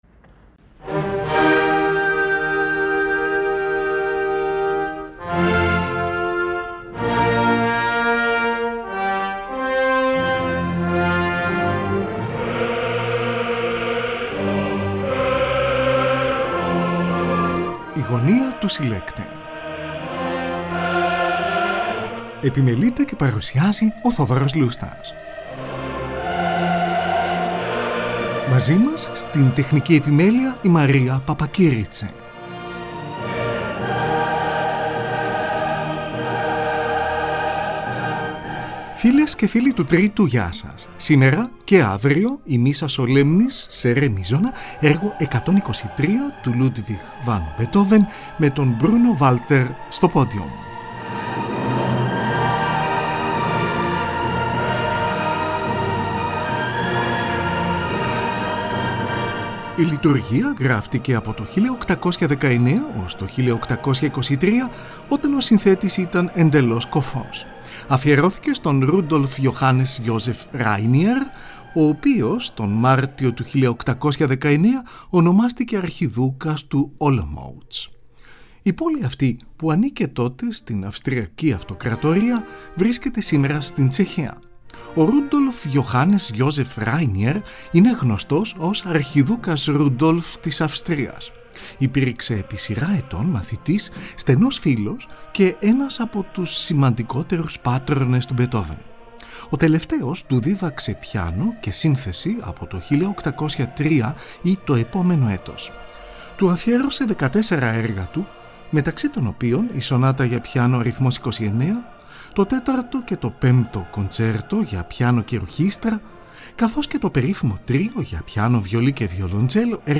Η MISSA SOLEMNIS ΤΟΥ BEETHOVEN ME TON BRUNO WALTER ΣΤΟ PODIUM (ΠΡΩΤΟ ΜΕΡΟΣ) Ludwig van Beethoven: Missa Solemnis, έργο 123 : Kyrie, Gloria, Credo.
Τη Χορωδία Westminster και τη Φιλαρμονική της Νέας Υόρκης διευθύνει ο Bruno Walter , από ζωντανή ηχογράφηση στο Carnegie Hall , στις 18 Απριλίου 1948.